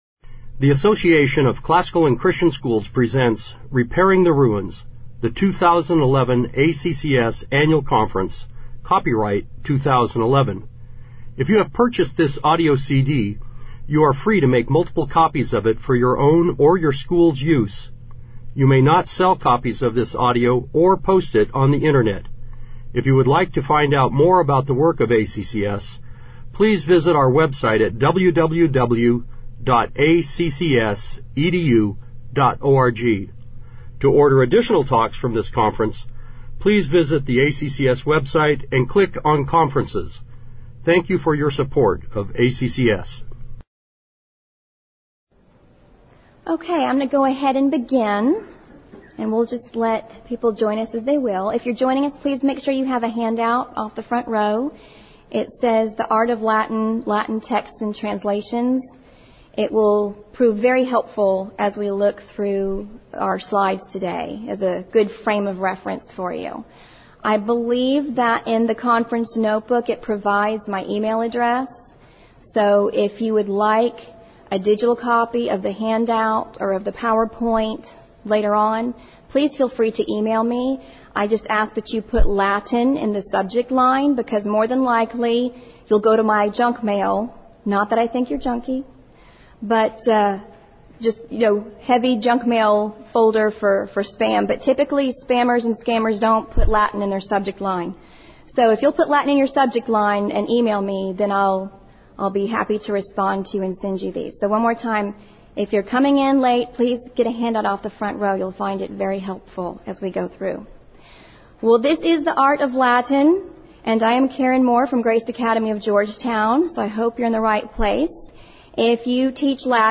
2011 Workshop Talk | 0:49:01 | All Grade Levels, Latin, Greek & Language
Bringing this artwork into the classroom will breathe new life into ancient texts. Whether you choose to read these works in Latin or in English, this workshop will demonstrate how to integrate art studies with the reading of the literature that inspired them. Speaker Additional Materials The Association of Classical & Christian Schools presents Repairing the Ruins, the ACCS annual conference, copyright ACCS.